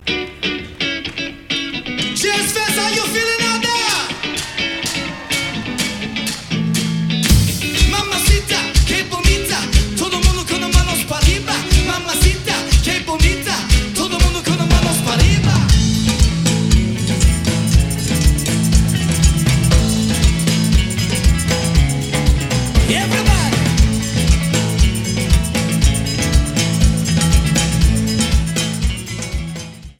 New Orleans, LA - May 1, 2014
Acura Stage
R&B
Rock